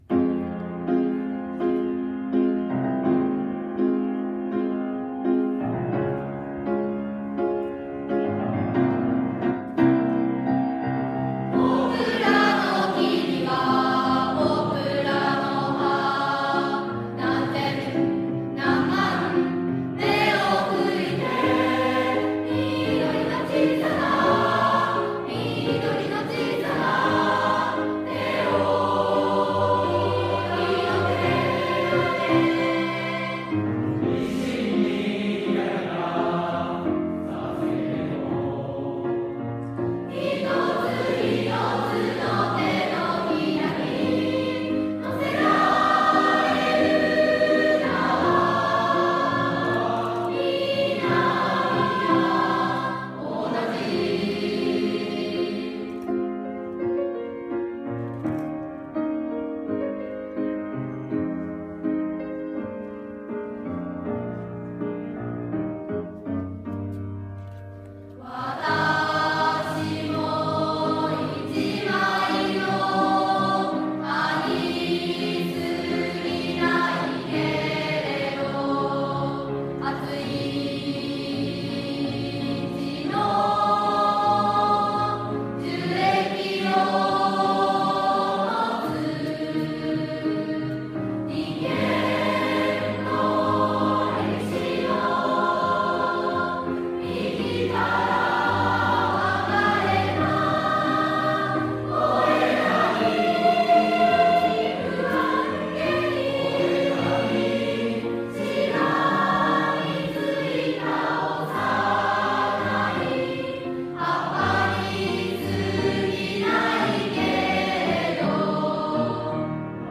• ３年全体合唱
文化祭でラストを演じた３年生全体合唱の
歌声をお聴きください→